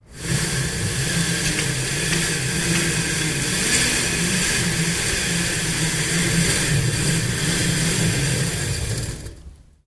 Venetian Blinds Being Let Down